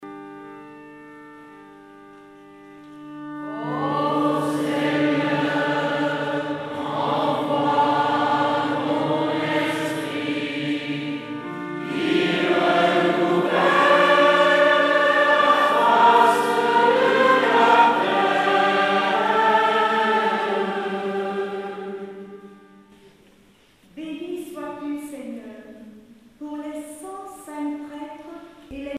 enfantine : prière, cantique
circonstance : dévotion, religion
Pièce musicale éditée